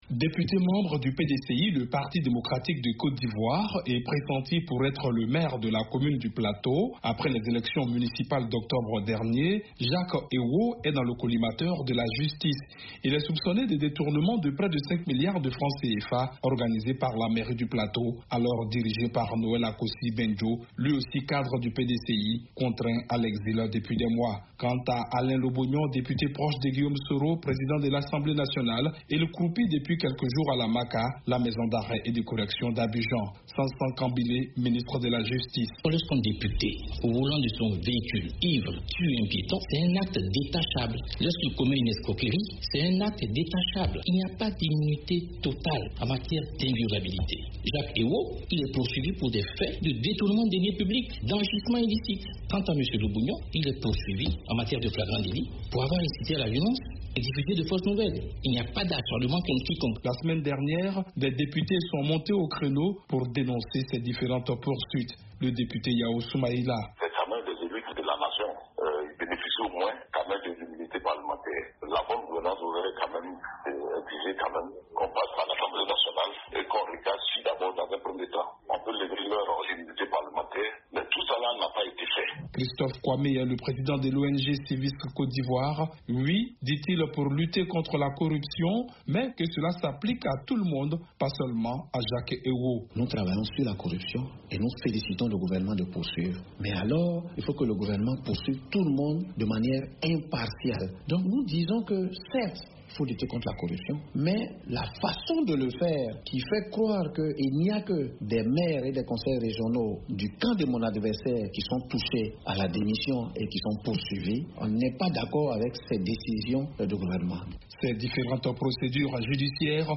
En Côte d’Ivoire, le climat politique reste tendu en dépit de la reprise du dialogue politique en vue de la réforme de la CEI, la Commission électorale indépendante. Un député est en prison et observe une grève de la faim tandis qu’un autre est sous la menace d’une arrestation. A Abidjan, les explications de notre correspondant